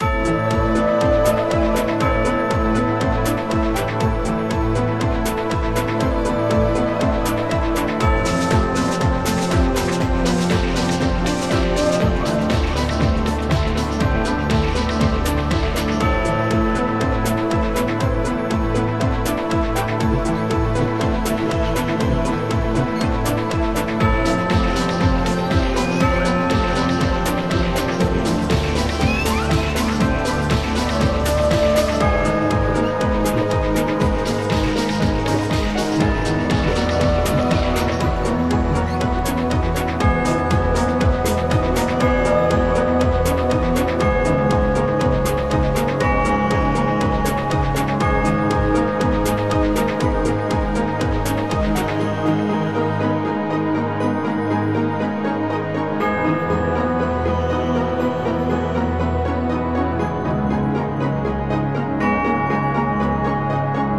Persönlich gefällt mir die Base noch nicht so richtig, vielleicht leiser, lauter oder auch weglassen?